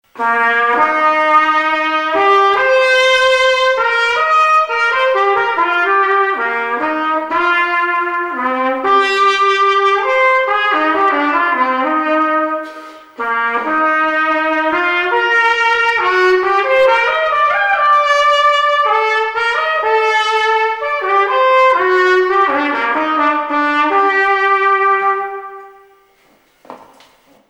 Please pardon the sound quality. I used my Sony camcorder, then ran the sound into my PC.
No editing was done to the sound clips other than adding a little reverb. But excuses aside, here are a few samples of this NY styled 43G belled Bach Strad: